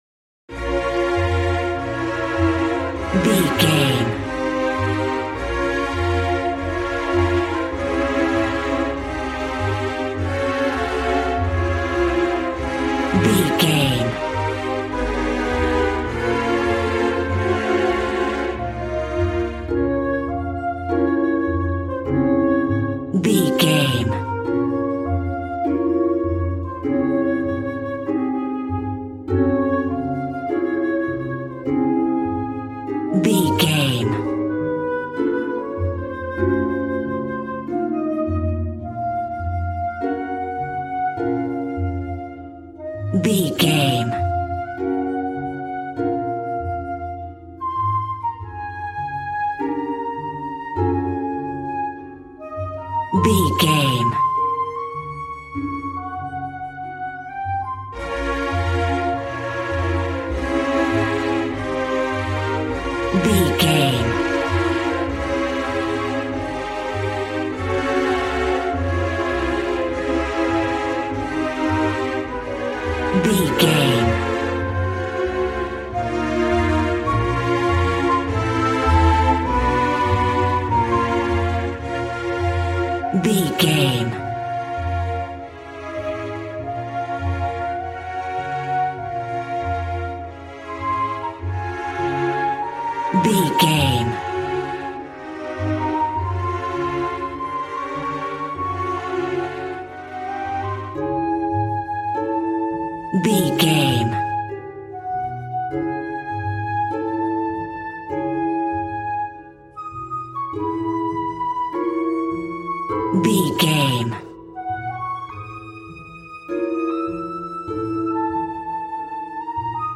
Ionian/Major
D♭
dramatic
epic
percussion
violin
cello